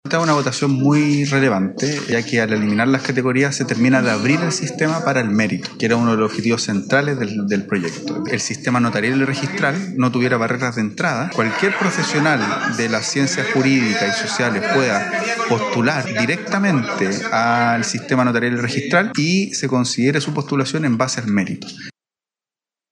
Tras la instancia, el ministro de Justicia, Jaime Gajardo, destacó que al eliminar las categorías prima el mérito, uno de los propósitos centrales del proyecto.
cuna-comision-mixta-notarios-jaime-gajardo-1.mp3